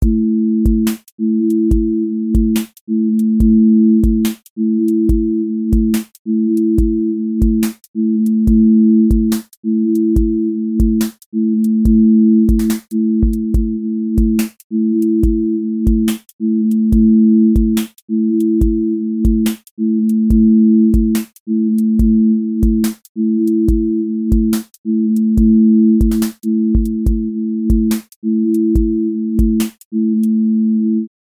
QA Listening Test drill Template: drill_glide